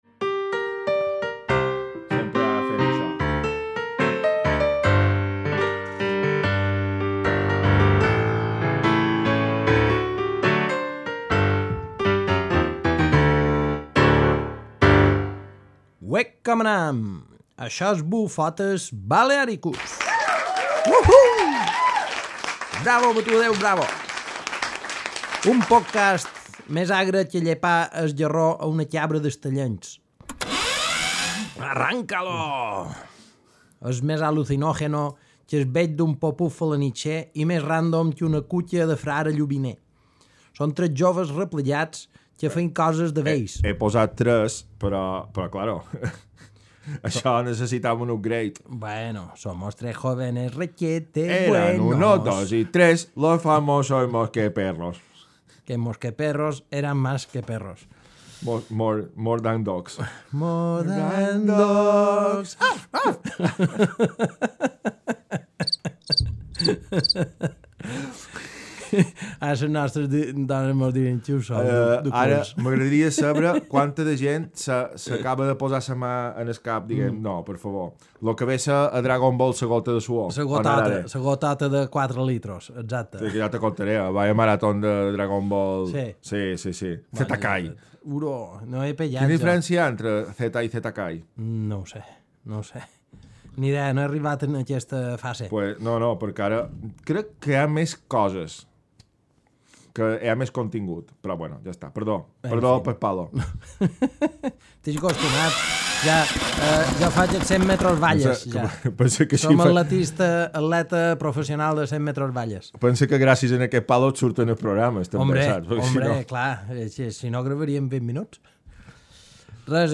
s’ordenador s’ha desordenat, per tant l’escoltarem fluixet una estona